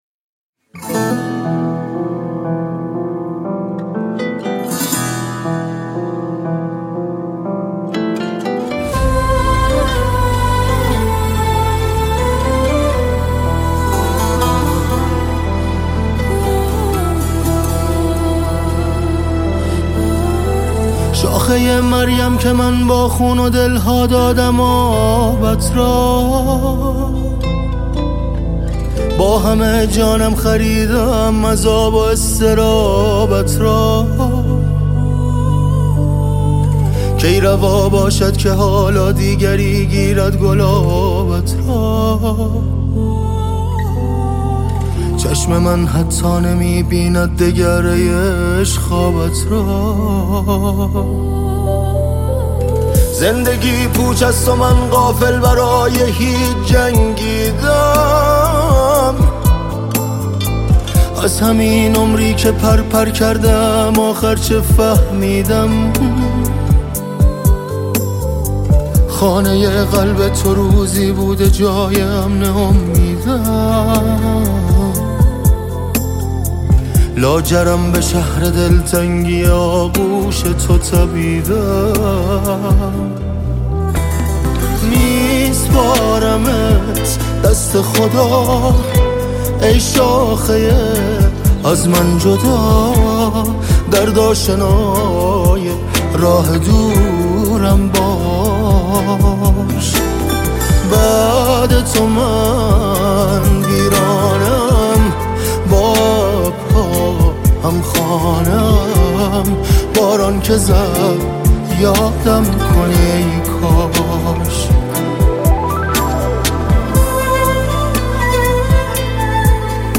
عاقا من اومدم با یکی دیگه از رشته آهنگ های چس ناله ای